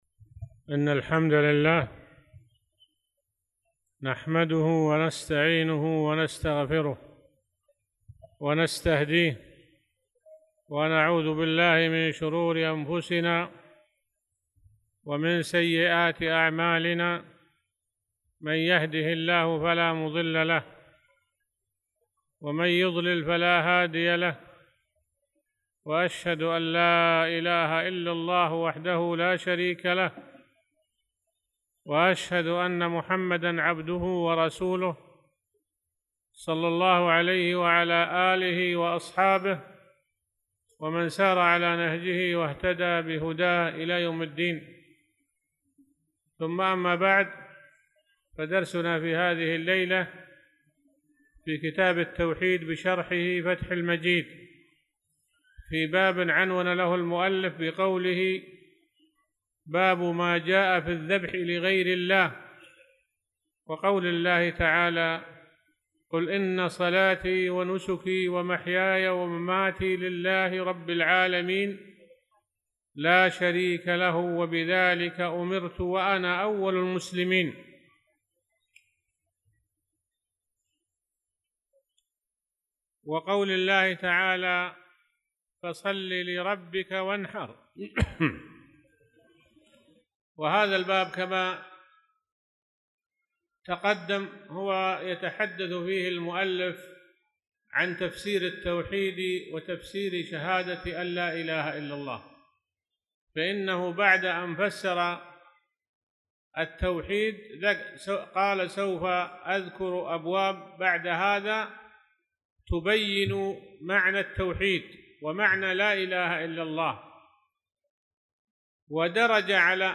تاريخ النشر ٦ ذو القعدة ١٤٣٧ هـ المكان: المسجد الحرام الشيخ